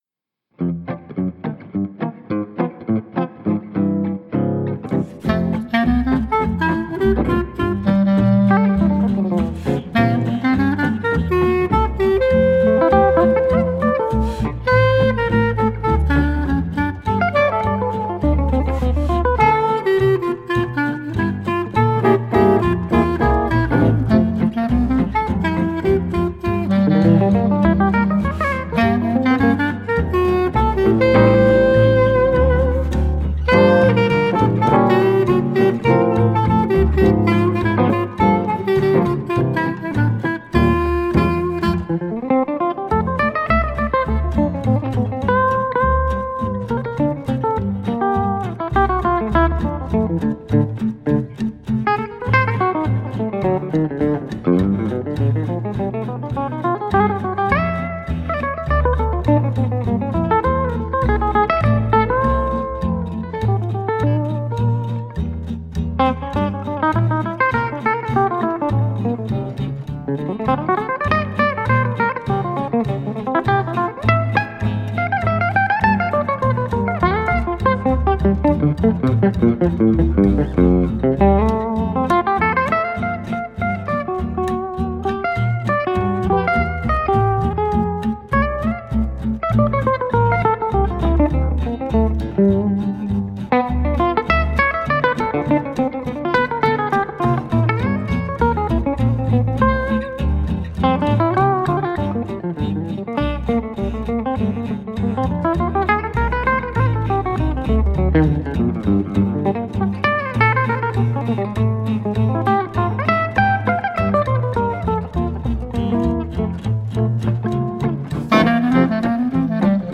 clarinette
guitare solo
contrebasse